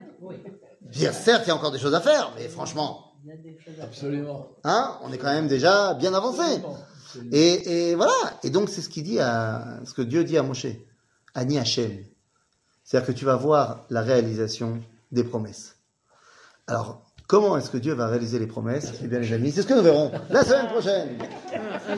שיעור מ 22 נובמבר 2023